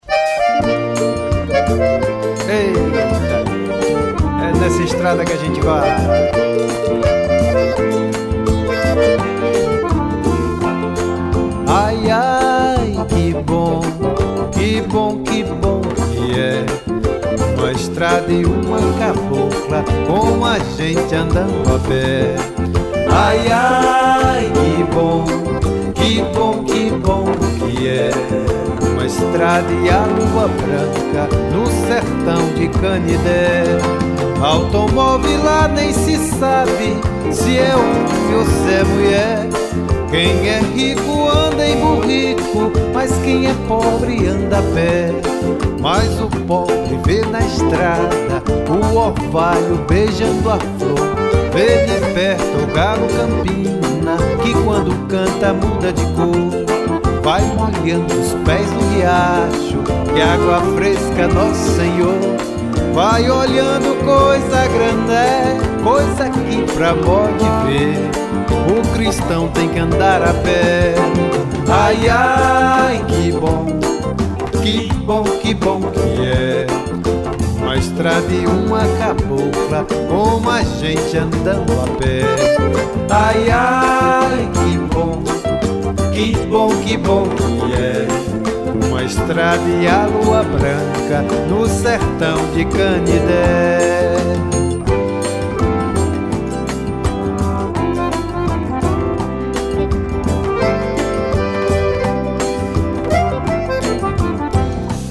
1082   01:44:00   Faixa:     Forró